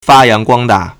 发扬光大 (發揚光大) fā yáng guāng dà
fa1yang2guang1da4.mp3